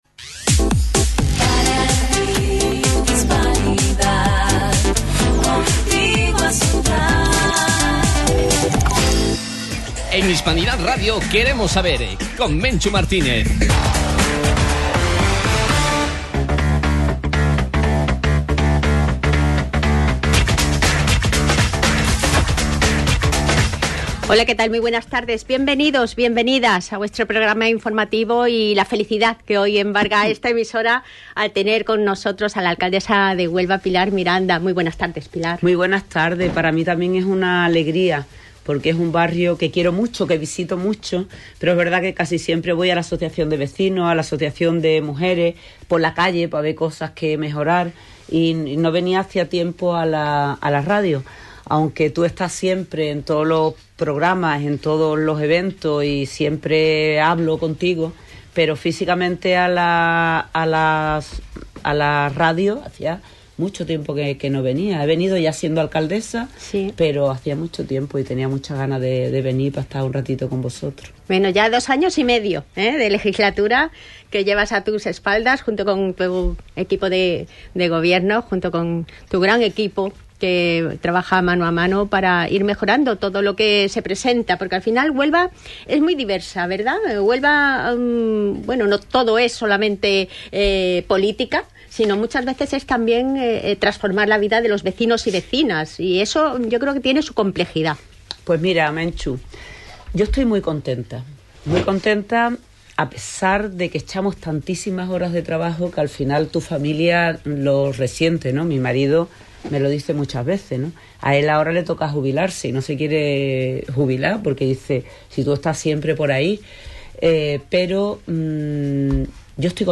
Hemos recibido en la emisora la visita de Pilar Miranda, Alcaldesa de Huelva. Nos informó del avance de la capital en estos dos años y medio de legislatura, los Proyectos que el Ayuntamiento está desarrollando, los próximos a acometer en sus barrios , las infraestructuras necesarias para progresar y las actuaciones que hay que poner en marcha para seguir avanzando.